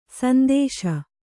♪ sandēśa